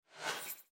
Шум воздушного потока при скроллинге содержимого экрана